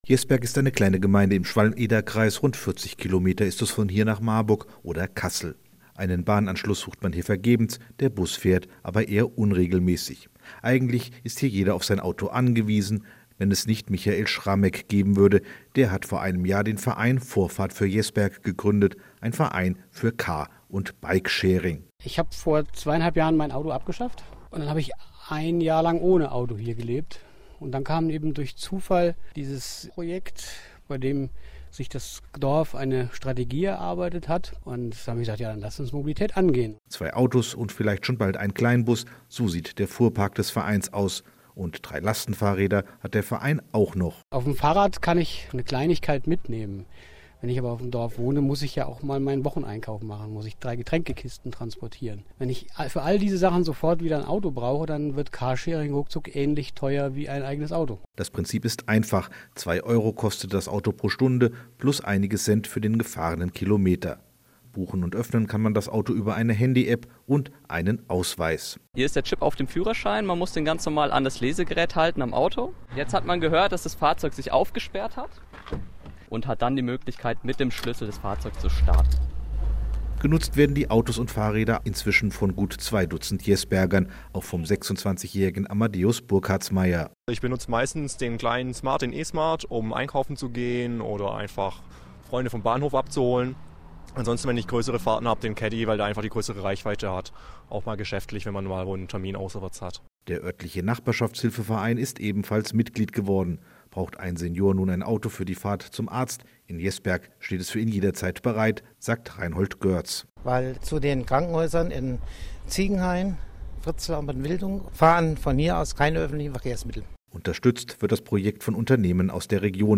Mai 2017: Nachdem zu Beginn des Monats die Fernsehabteilung des HR zu Besuch in Jesberg war, ließ es sich das HR1 nicht nehmen, ebenfalls über die Projekte mit Vorbildfunktion von VoJes zu berichten. In dem knapp 3 minütigen Beitrag erfährt der Zuhörer unter anderem über die Einsatzmöglichkeiten der CarSharing-Fahrzeuge und der E-Lastenräder.